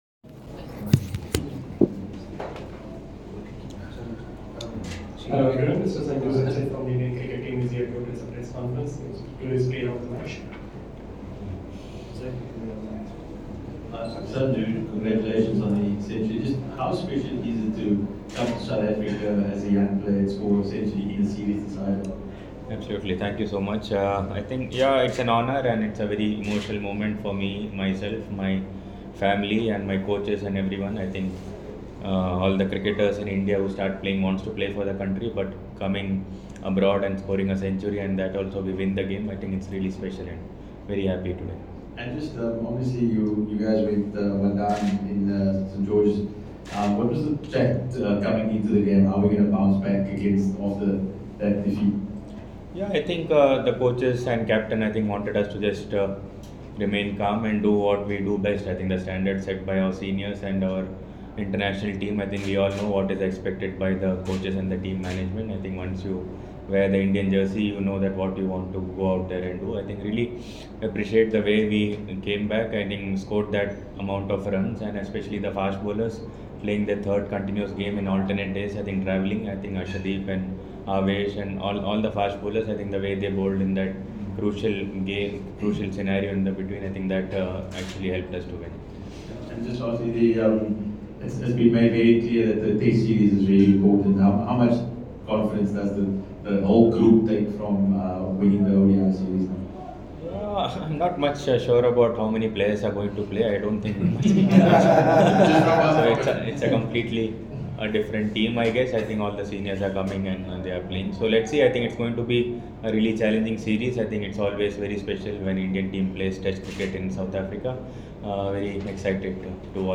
Sanju Samson Speaks to Media in Paarl after Final SA ODI
Catch Sanju Samson's insights and thoughts as he addresses the press in Paarl following the conclusion of the thrilling ODI series against South Africa.